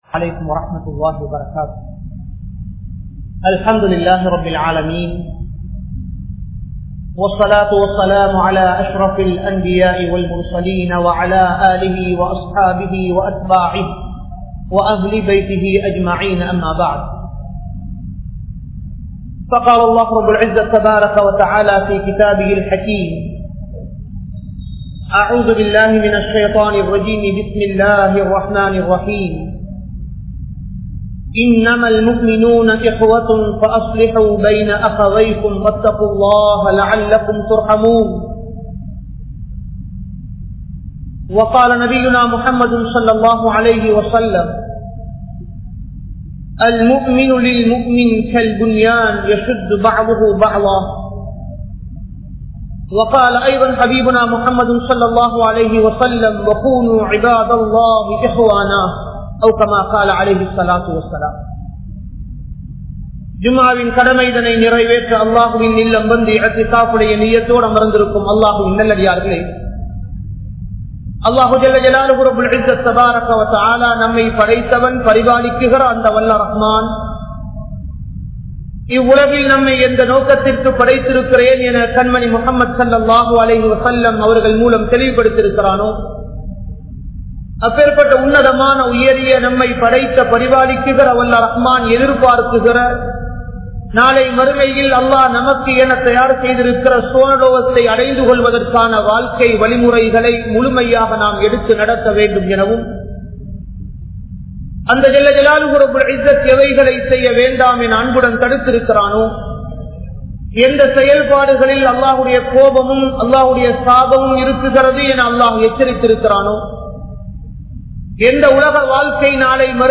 Samooha Ottrumai(Social Unity) | Audio Bayans | All Ceylon Muslim Youth Community | Addalaichenai
Colombo 03, Kollupitty Jumua Masjith